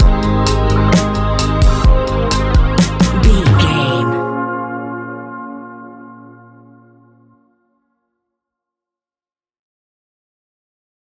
Ionian/Major
A♯
chilled
laid back
Lounge
sparse
new age
chilled electronica
ambient
atmospheric
instrumentals